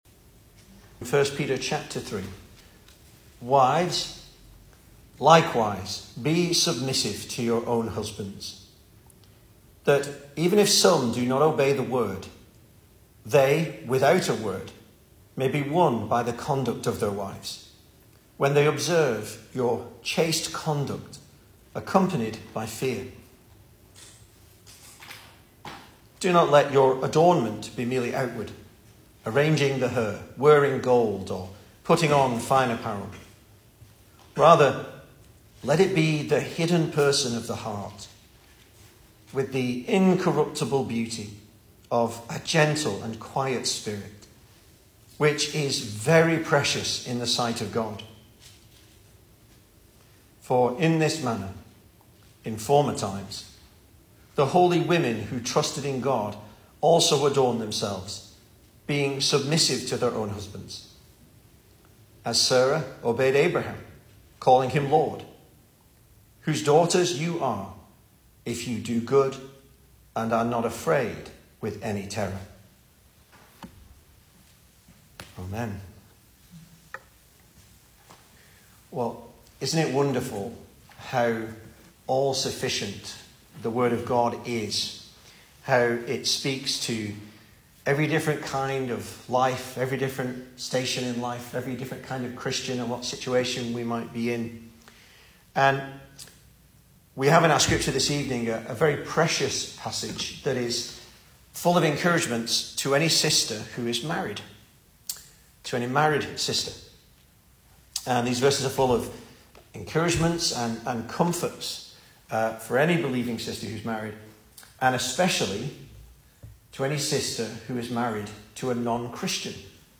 2023 Service Type: Weekday Evening Speaker